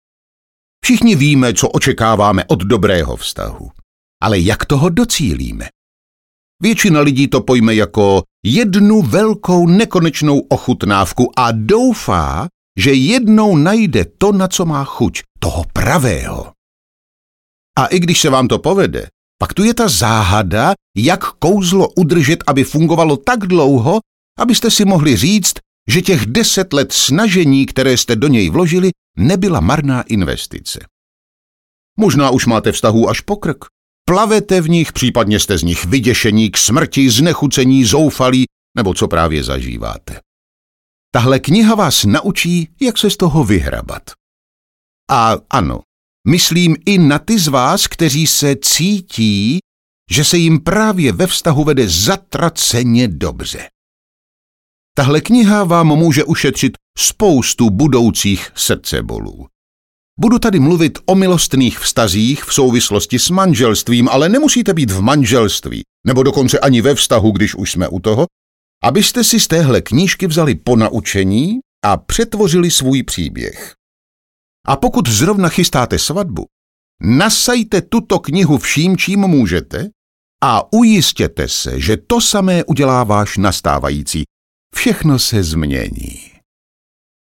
Láska ku*evská audiokniha
Ukázka z knihy